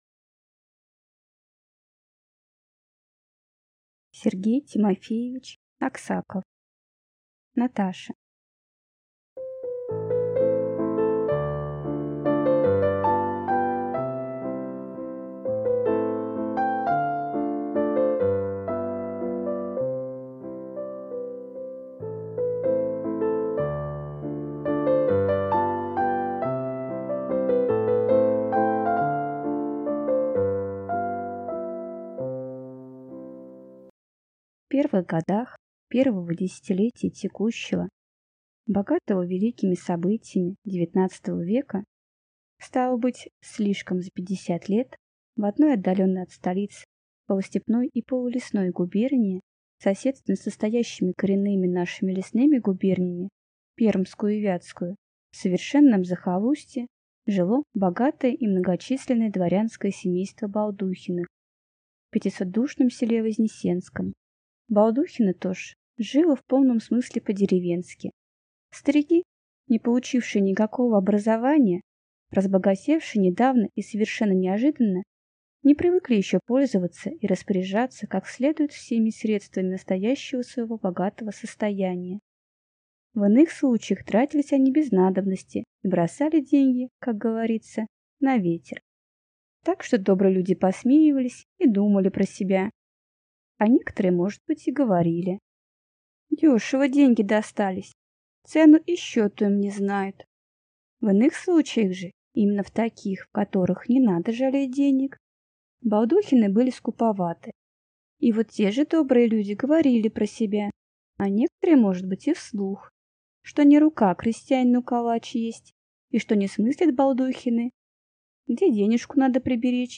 Аудиокнига Наташа | Библиотека аудиокниг